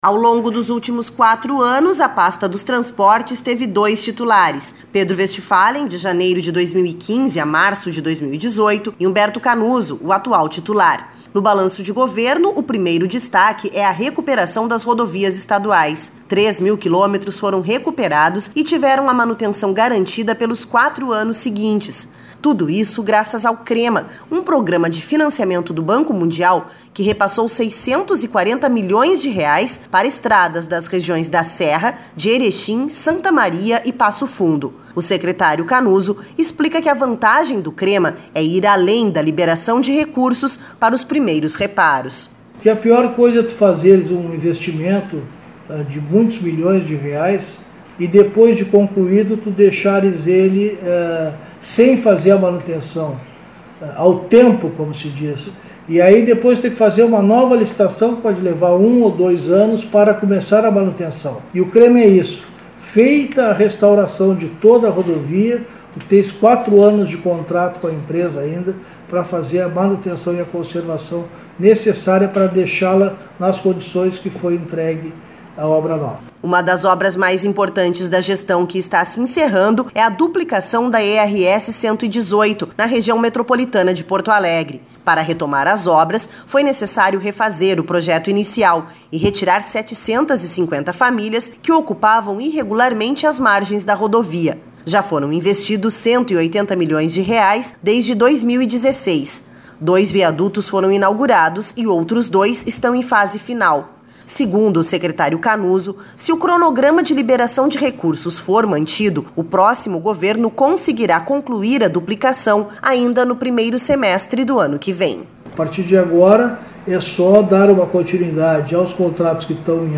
Nesta entrevista de balanço de gestão, Humberto Canuso destaca os desafios, as dificuldades, mas sobretudo as realizações conseguidas pela pasta nos últimos quatro anos.